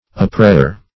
Oppressure \Op*pres"sure\